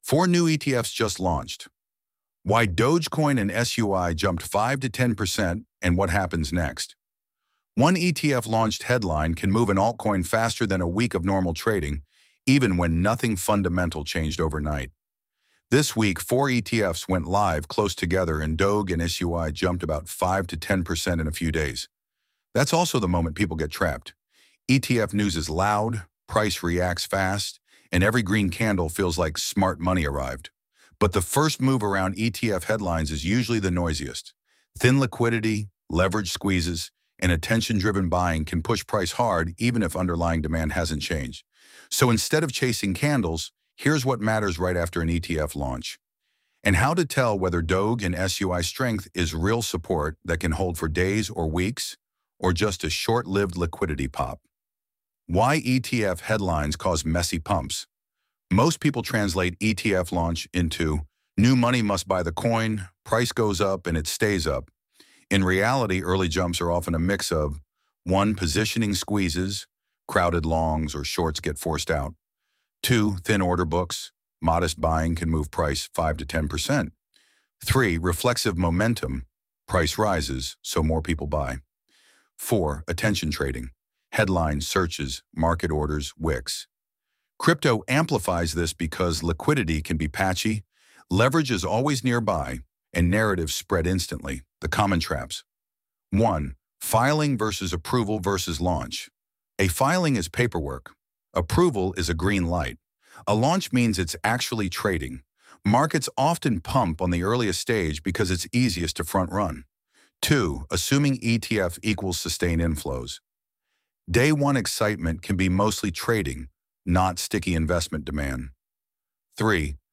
4-New-ETFs-Just-Launched-—-Why-Dogecoin-and-SUI-Jumped-5–10-in-Days-audio-article.mp3